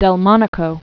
(dĕl-mŏnĭ-kō), Lorenzo 1813-1881.